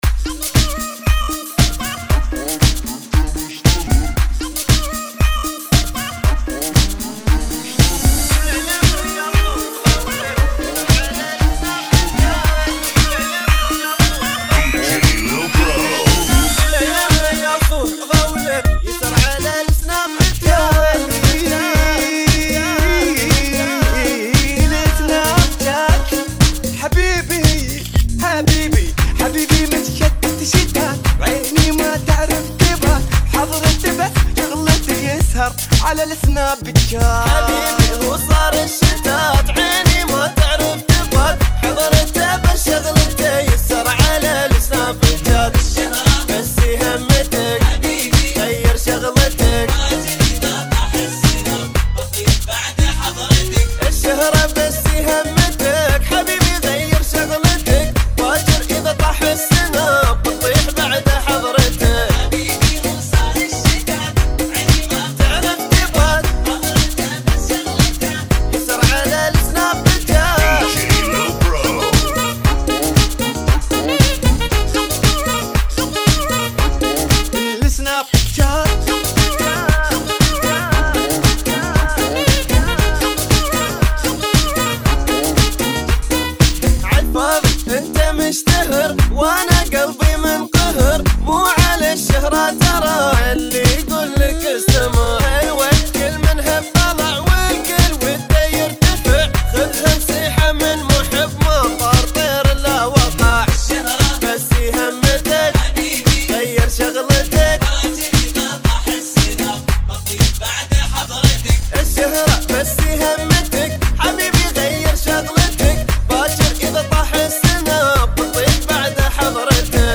[ 116 bpm ]